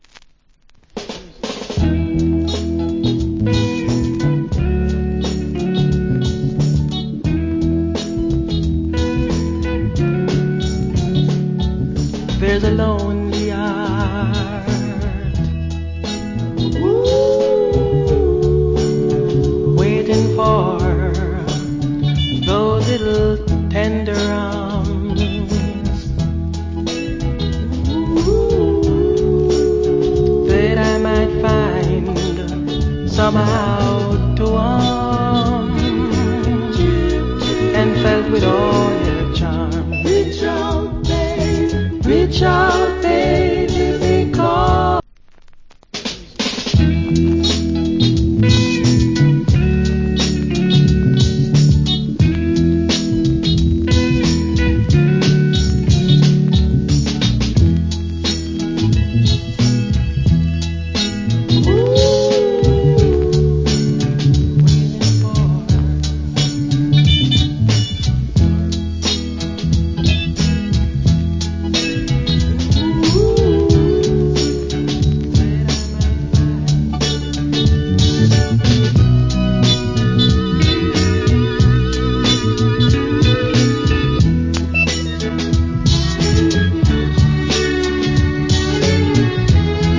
Cool Jamaican Soul Vocal.